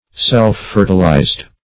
\Self`-fer"ti*lized\